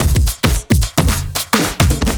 OTG_TripSwingMixA_110b.wav